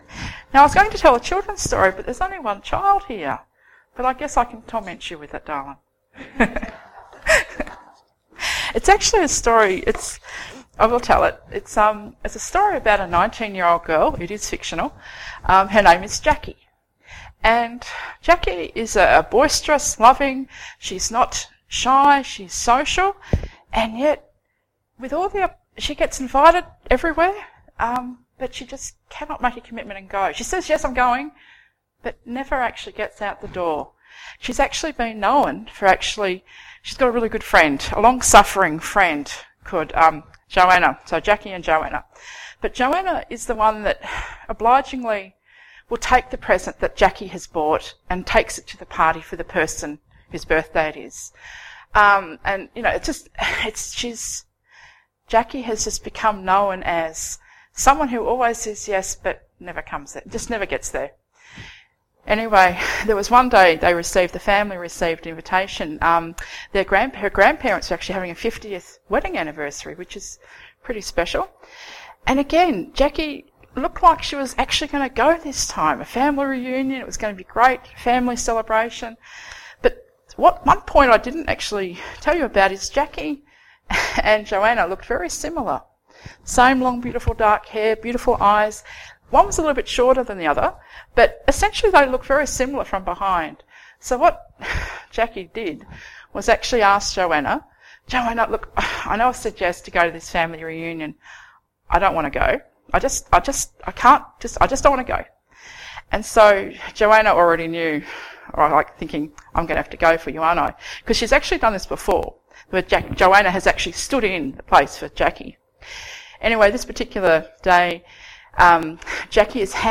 Children's Stories